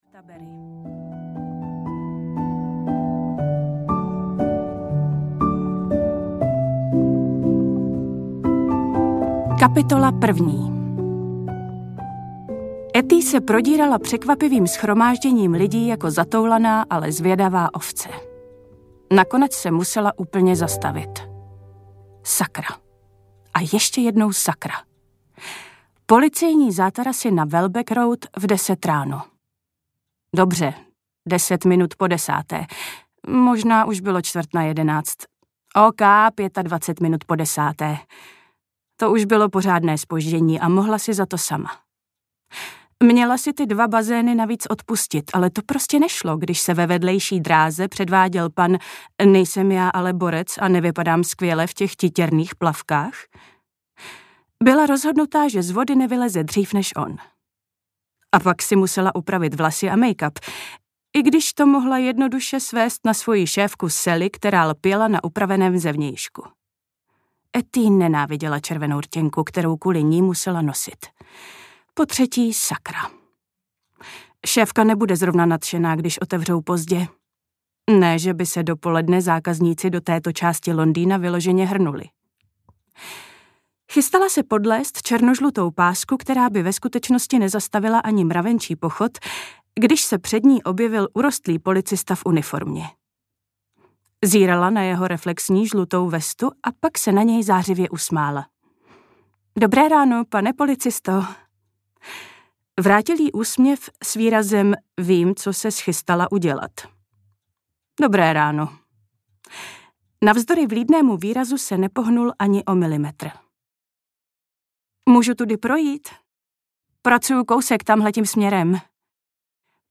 S láskou od jezera audiokniha
Ukázka z knihy
• InterpretAnna Fixová